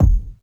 Medicated Kick 27.wav